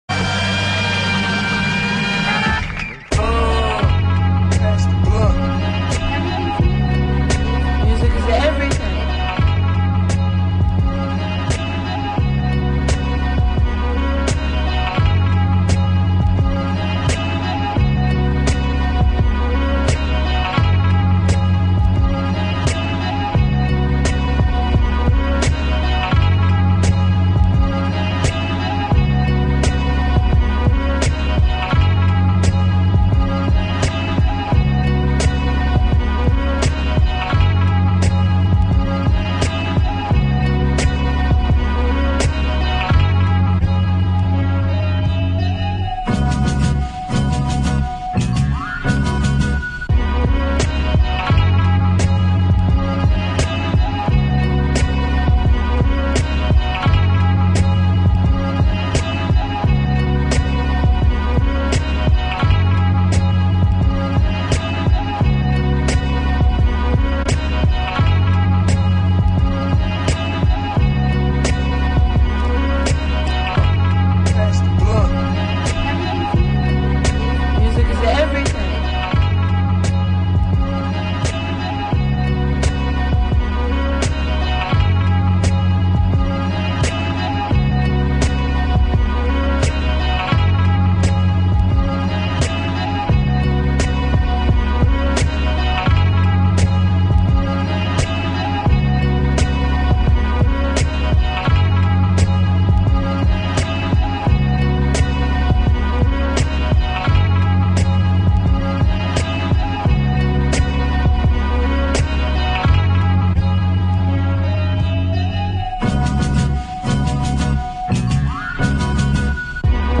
Hip Hop Funk